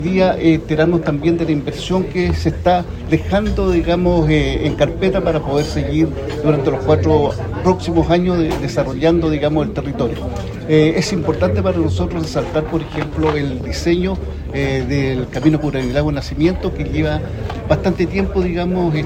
El alcalde de Curanilahue, Luis Gengnagel, destacó el diseño del camino que conecta la comuna con Nacimiento, que calificó de “crucial” para la futura conexión entre las provincias de Arauco y Bío Bío.